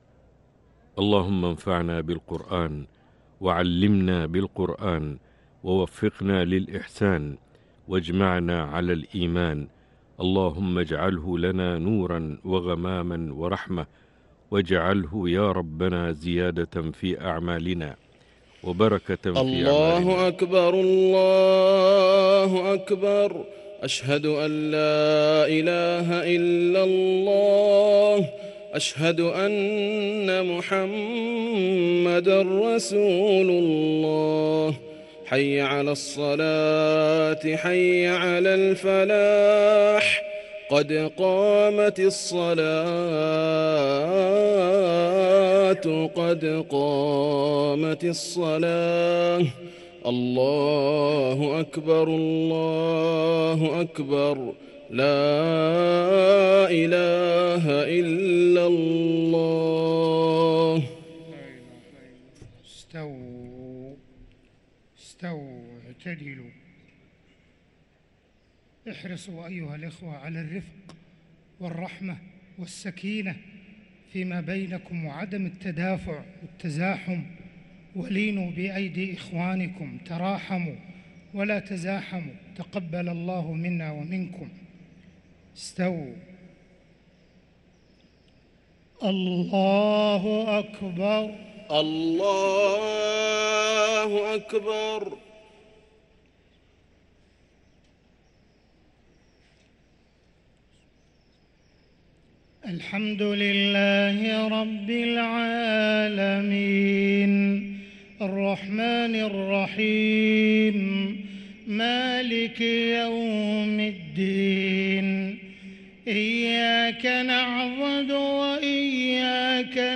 صلاة المغرب للقارئ صلاح البدير 26 رمضان 1444 هـ
تِلَاوَات الْحَرَمَيْن .